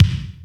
SINGLE HITS 0019.wav